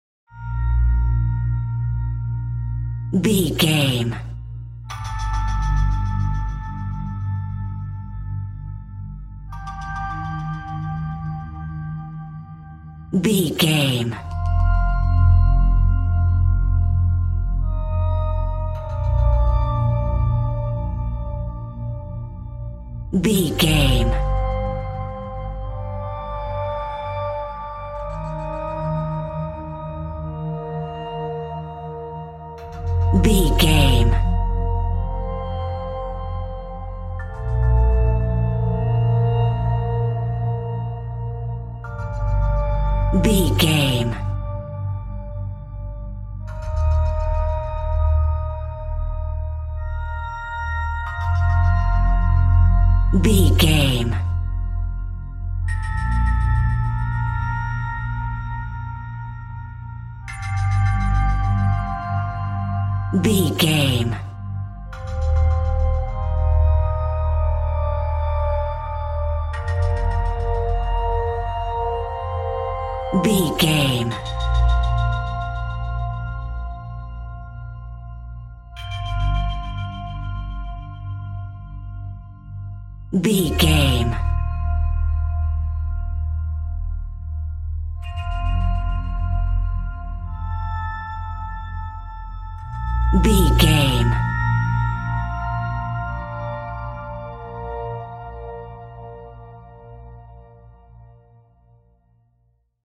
Ionian/Major
B♭
Slow
mellow
synth
flute
piano
meditative
melancholy
dreamy
ethereal
percussion